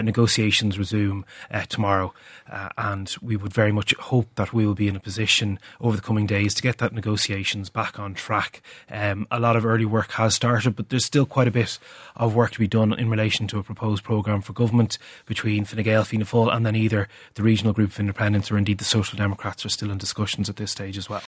While the Regional Independents group is expected to be part of the next coalition, Fine Gael TD Neale Richmond says the Social Democrats are still in talks: